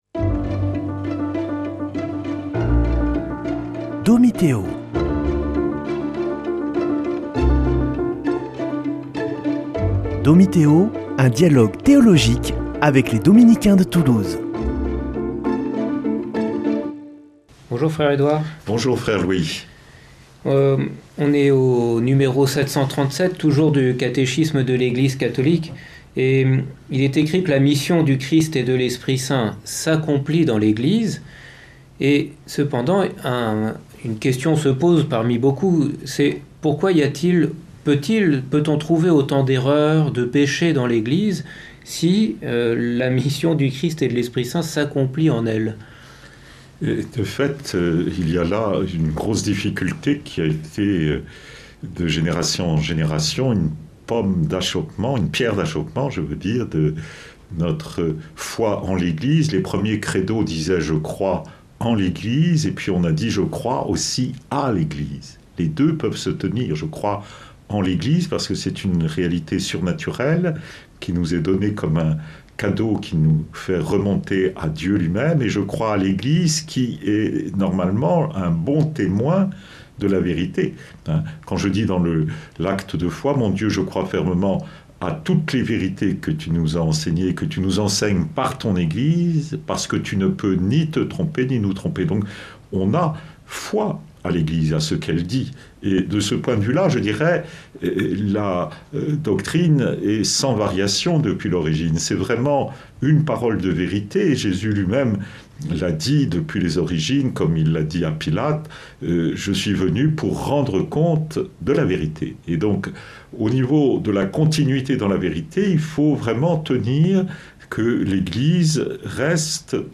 Une émission présentée par Dominicains de Toulouse